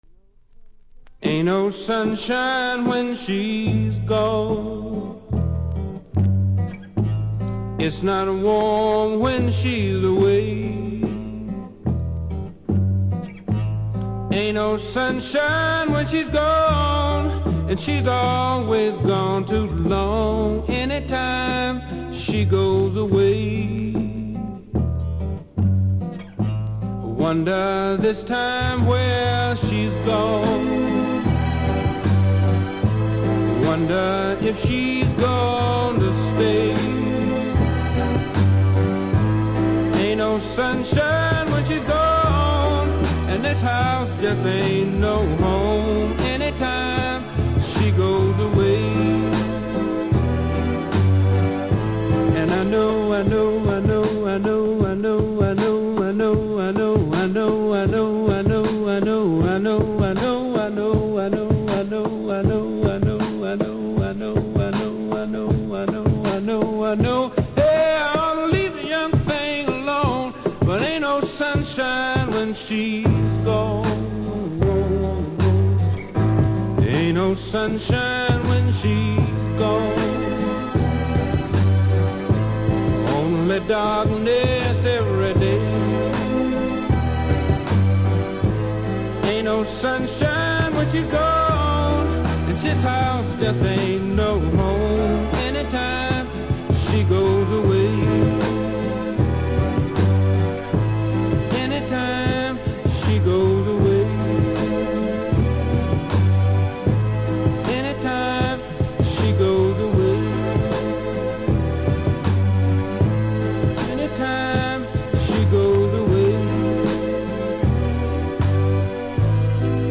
キズ多めノイズも感じますので試聴で確認下さい。